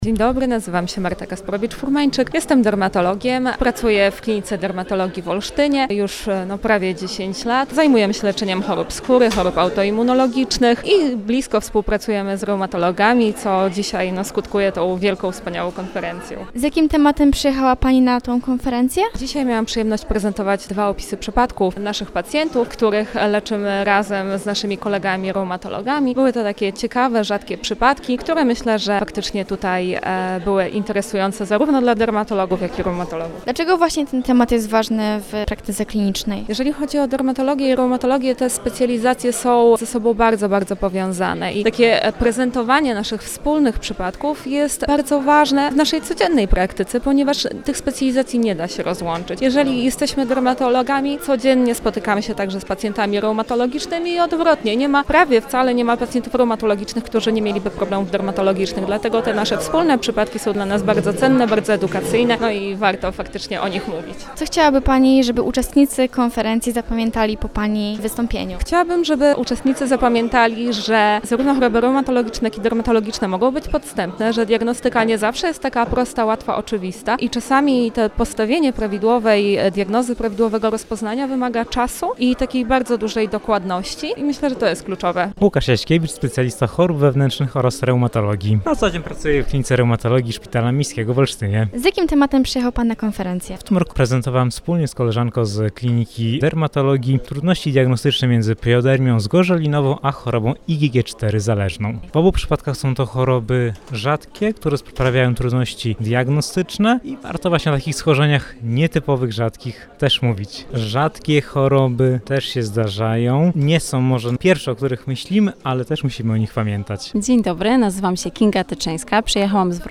Nasza reporterka rozmawiała także z uczestnikami konferencji „Skóra i Kości”.